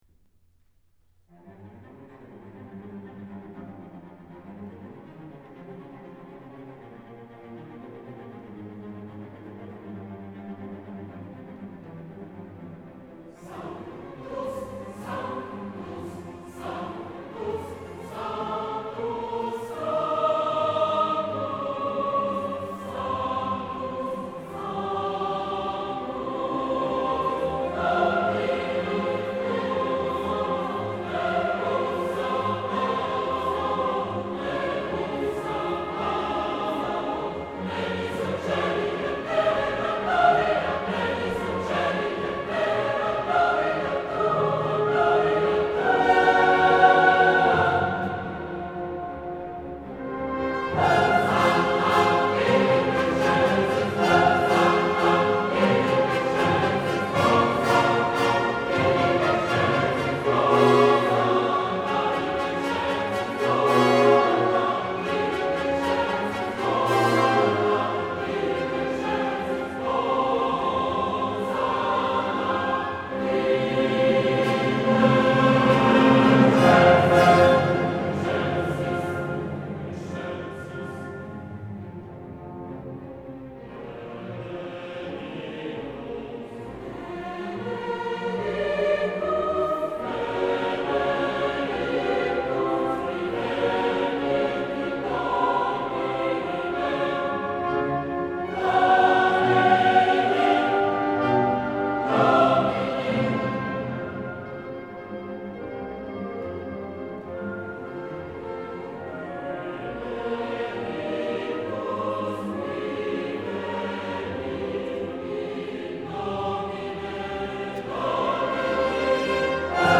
4 Sanctus, Benedictus | Messe de Saint-Nicolas – CHORALE CHOEUR TOLOSA